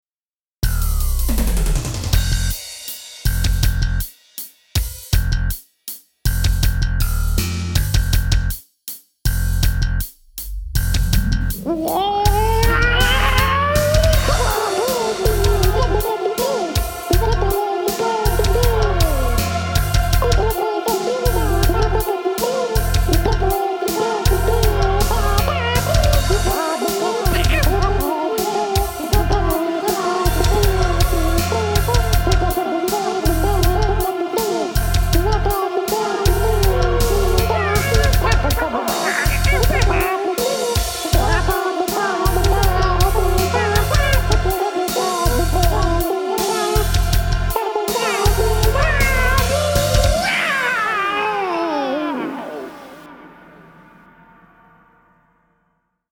12gothhiphopbirbs.mp3